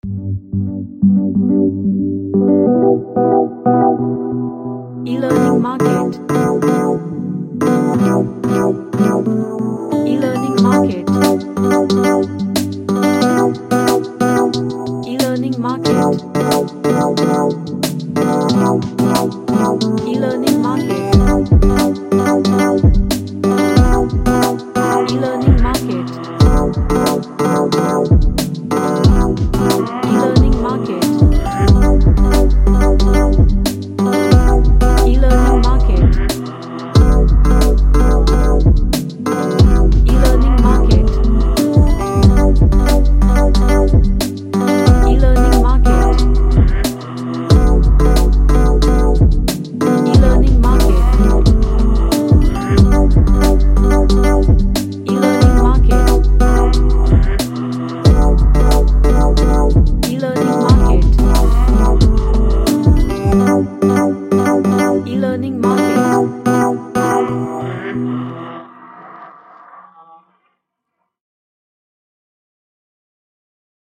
A chill lofi track
Strange / Bizarre